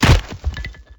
Impact.ogg